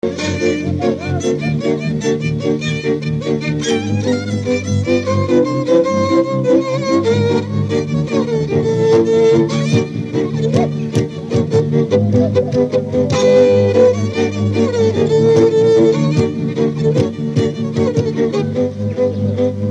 Dallampélda: Hangszeres felvétel
Erdély - Szolnok-Doboka vm. - Szék
hegedű
kontra
bőgő
Műfaj: Porka
Stílus: 4. Sirató stílusú dallamok